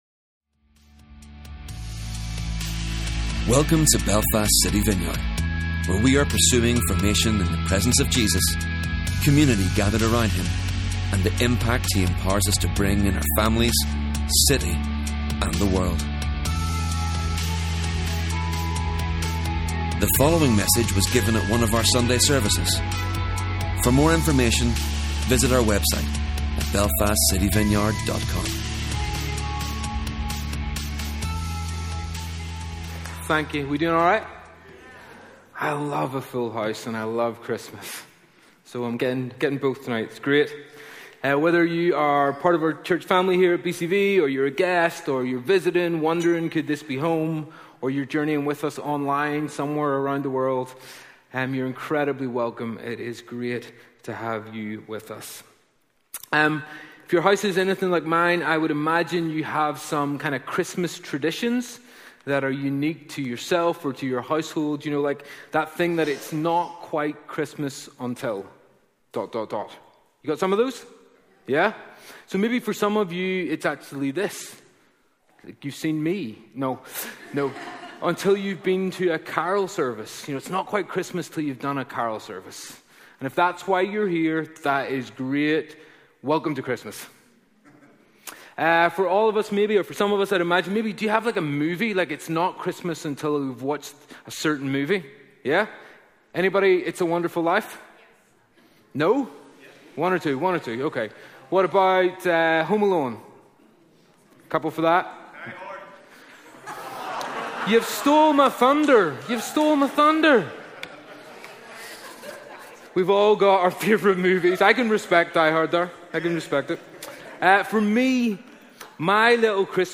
Celebration Service | Belfast City Vineyard
candles-carols-2025.mp3